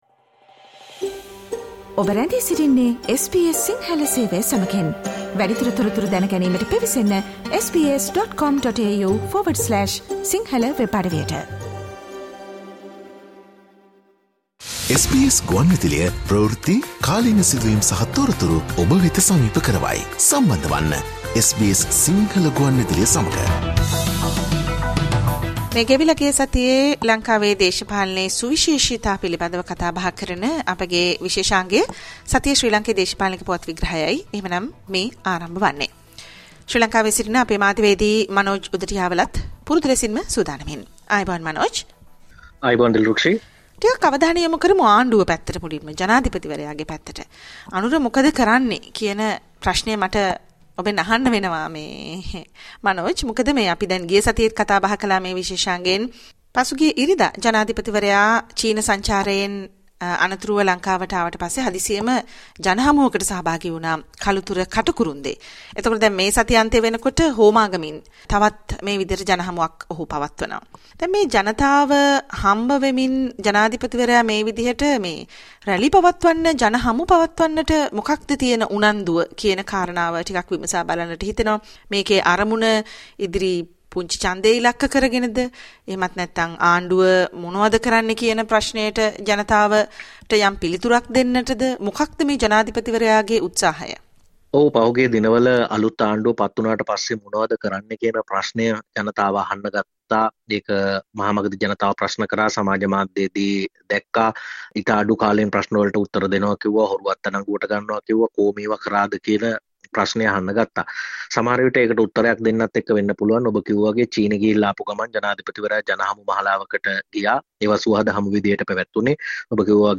Please note that the two segments broadcasted during the live program have been combined and are now available on the website as a single program.